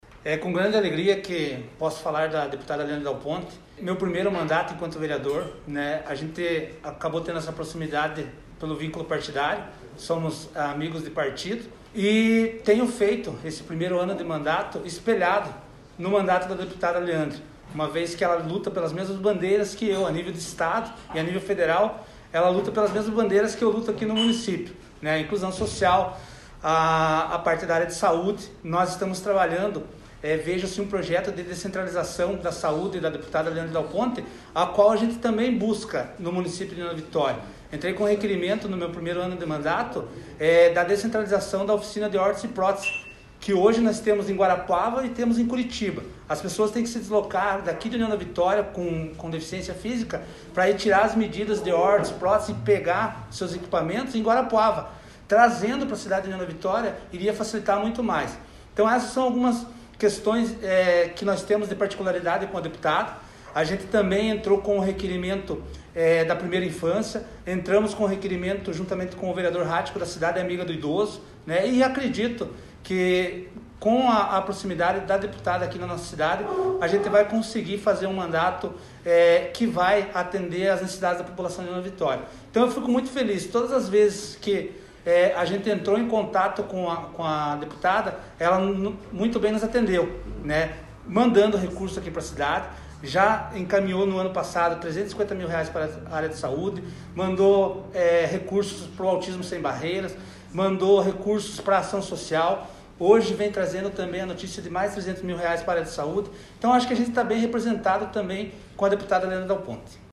Acompanhe o áudio do vereador abaixo: